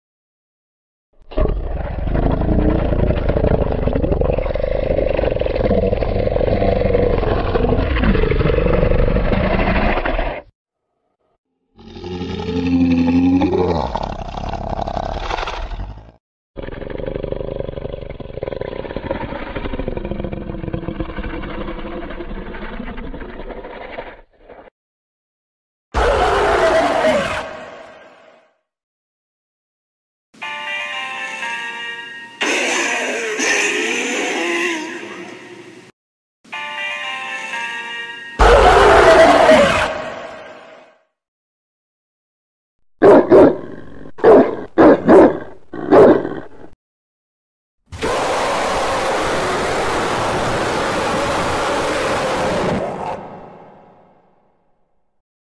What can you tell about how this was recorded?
• Quality: High